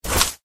umbrella_close_02.ogg